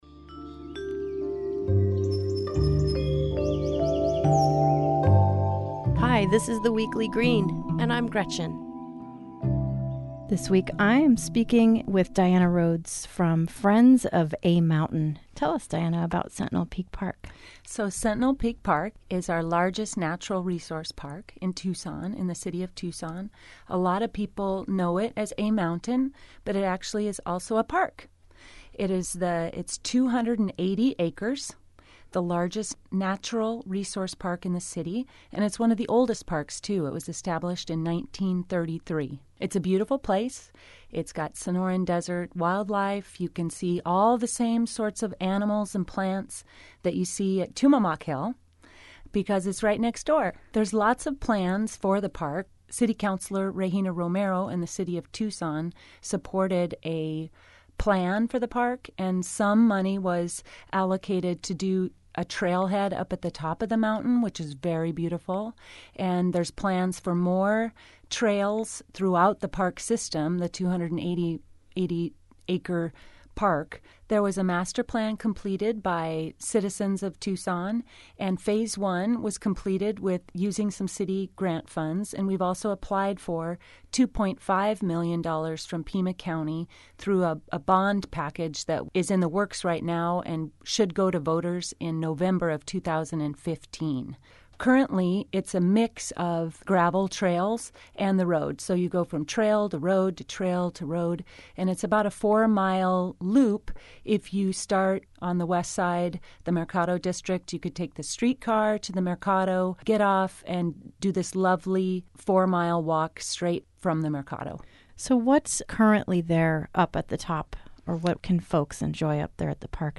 KXCI Community Radio